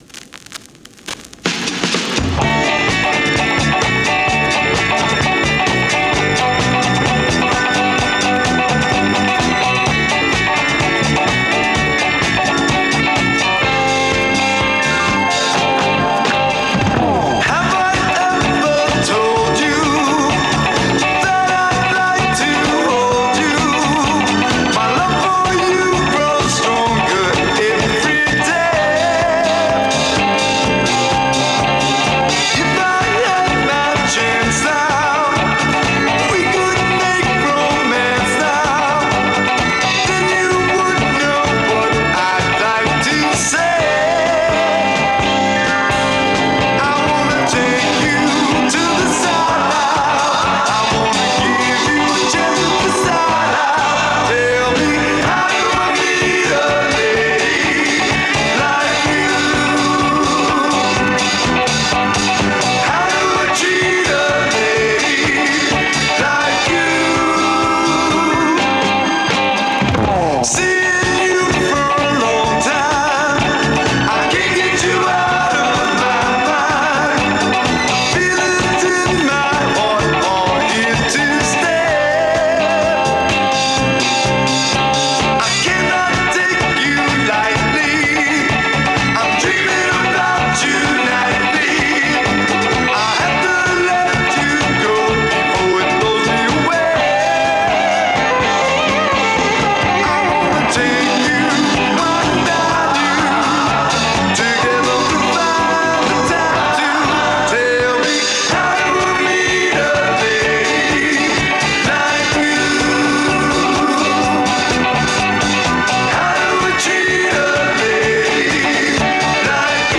bass guitar
drums
Lead guitar
Piano
Special keys